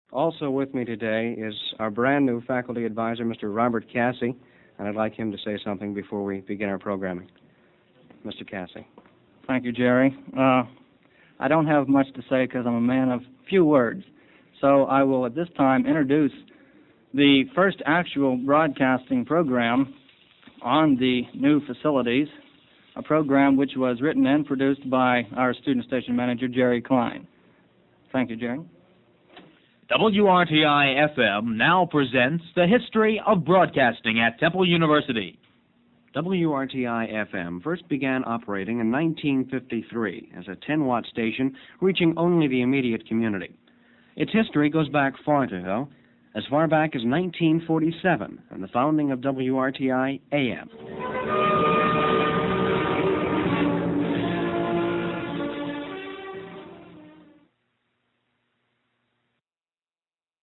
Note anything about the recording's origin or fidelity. The First Broadcast from Annenberg Hall